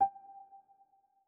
Raise-Your-Wand / Sound / Effects / UI / Modern7.wav